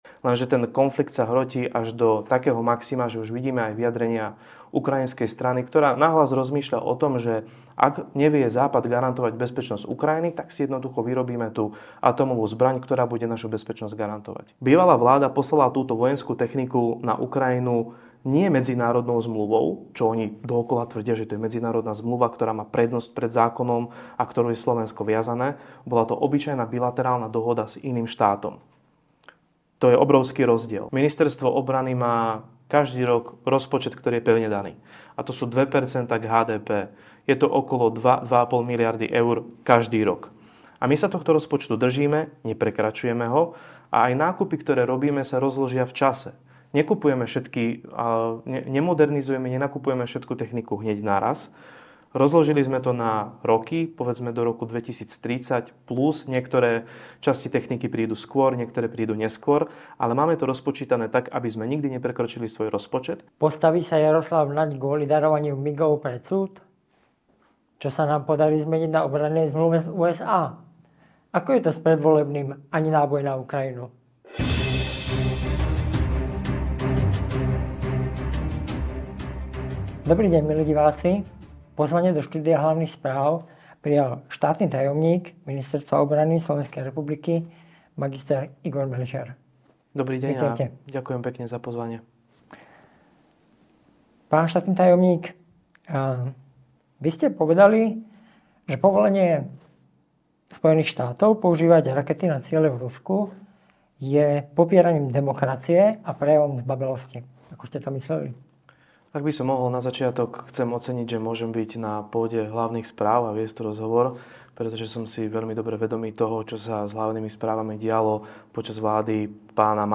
Stiahnite si alebo vypočujte audio záznam rozhovoru